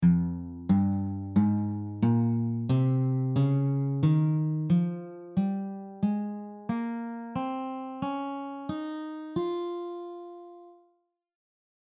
Each scale below will cover two octaves on the guitar in standard notation with tabs and audio examples included.
F minor scale
The notes of the F natural minor scale are F, G, Ab, Bb, C, Db, and Eb.
F-minor-Fm-scale-audio.mp3